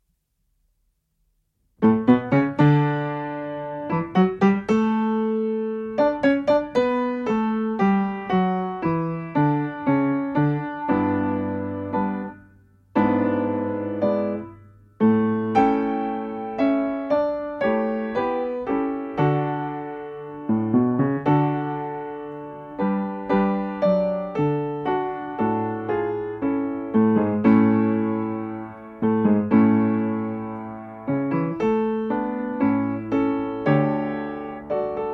Produkt zawiera nagranie akompaniamentu pianina
Nagrane z metronomem.
I część: 116 bmp
Nagranie dokonane na pianinie Yamaha P2, strój 440Hz
piano